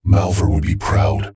[RA2/YR] Voice of Toxic Mech "Violence"XK-06
Subject description: A set of voice with metallic feel   Reply with quote  Mark this post and the followings unread